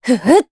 Aselica-Vox_Casting2_jp.wav